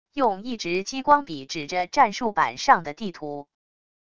用一直激光笔指着战术板上的地图wav音频